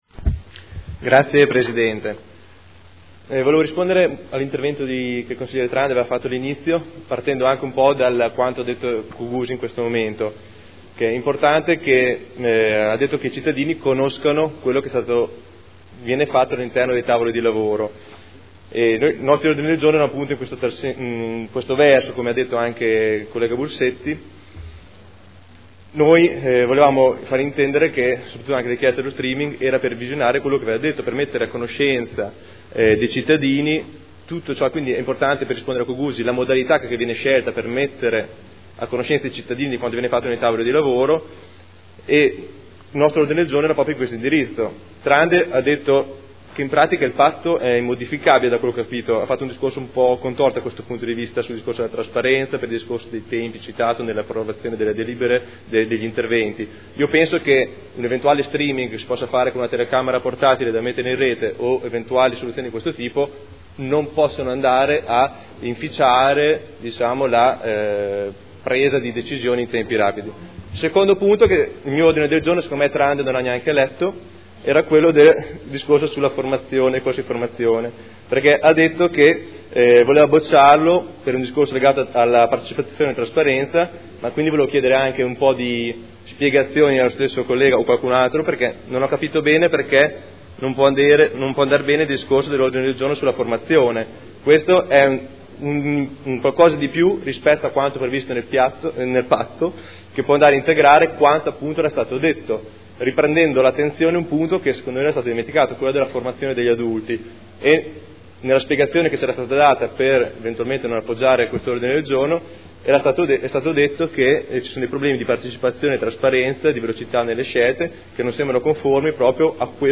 Dibattito su Ordini del Giorno e Mozione aventi per oggetto "Patto per Modena"
Audio Consiglio Comunale